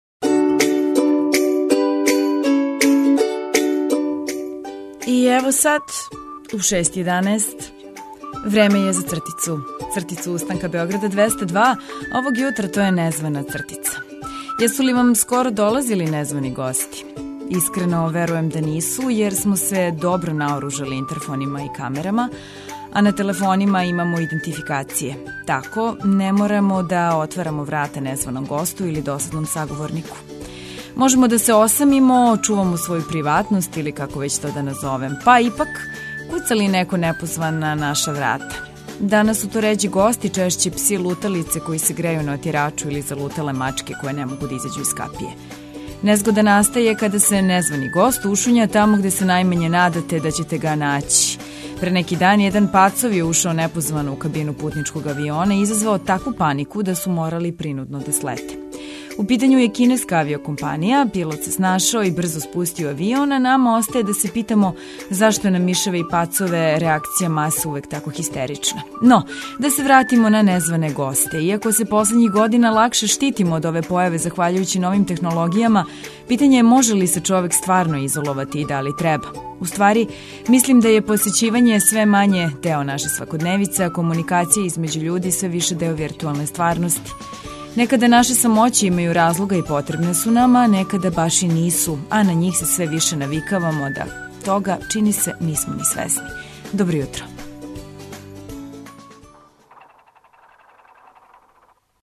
Започињемо радну недељу музиком за размрдавање, новим вестима, актуелностима, сервисним информацијама и новостима које је значајно знати изјутра. Имамо и репортера који преноси атмосферу са улица Београда.